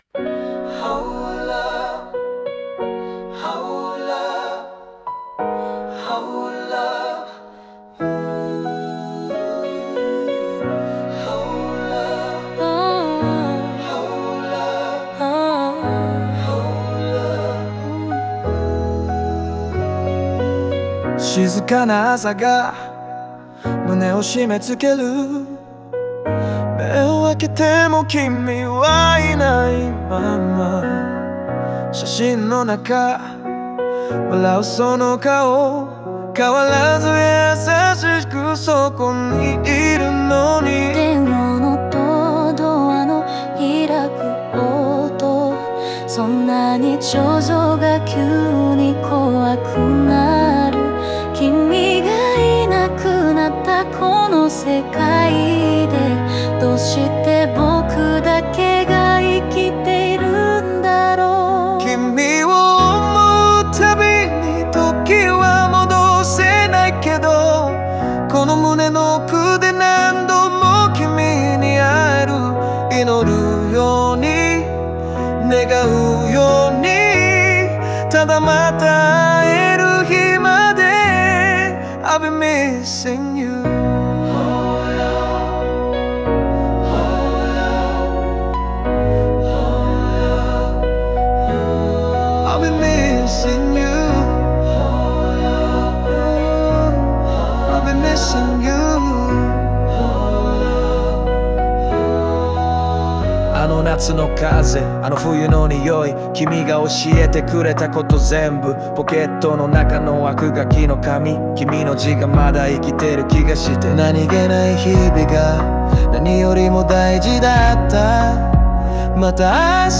静かなピアノと教会風オルガンが祈りのような空気を生み、男性の低い語りと女性の優しい歌声が交互に心情を語る。
ラストには「ありがとう」という囁きが残り、悲しみと愛が静かに溶けていく。